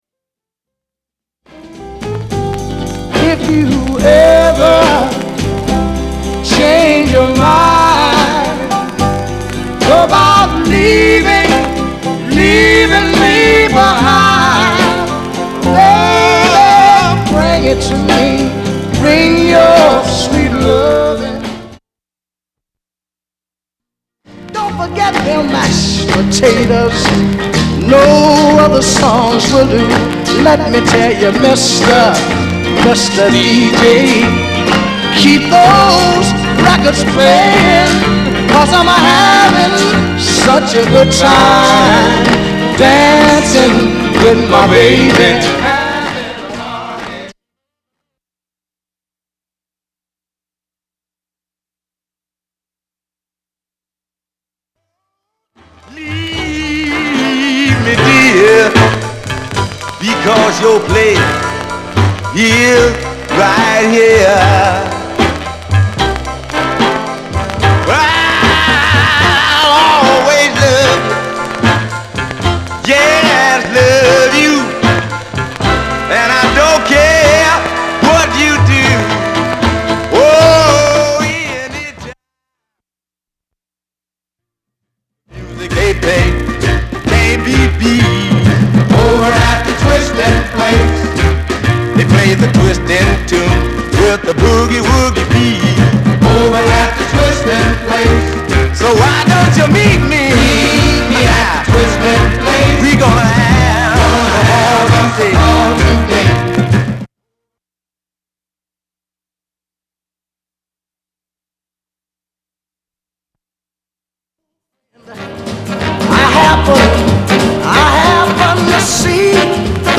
R&B、ソウル
/盤質/両面全体的に細かい傷ありり/US PRESS